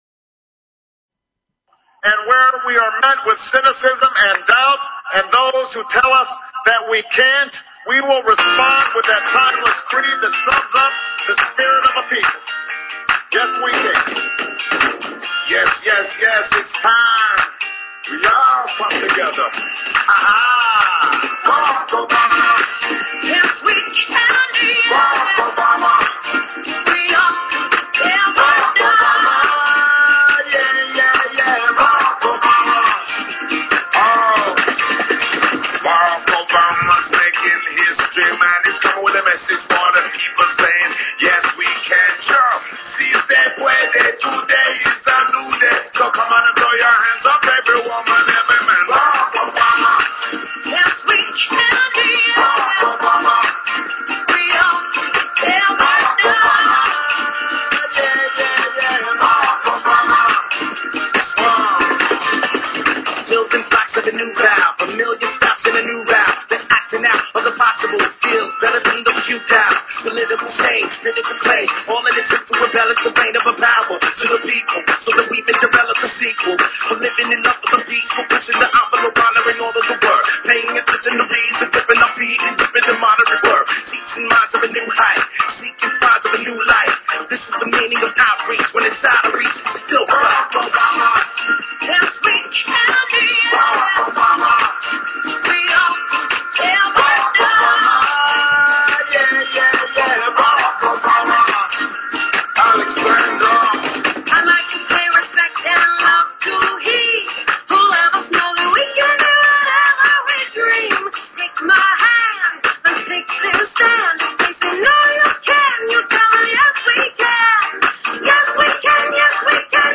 Talk Show Episode, Audio Podcast, The_Galactic_Round_Table and Courtesy of BBS Radio on , show guests , about , categorized as